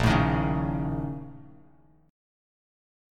A#mM7bb5 chord